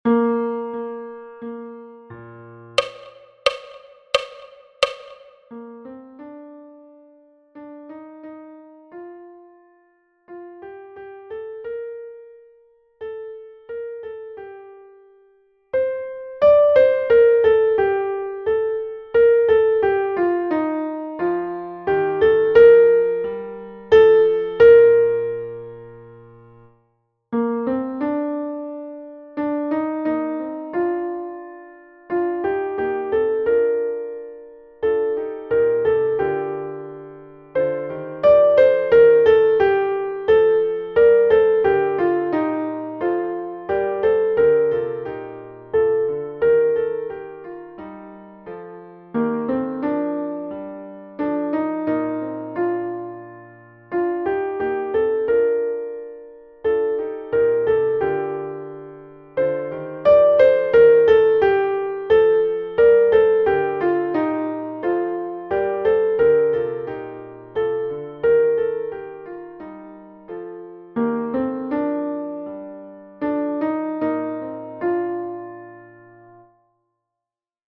Eklektika rehearsal music.
sing-a-song-sop.mp3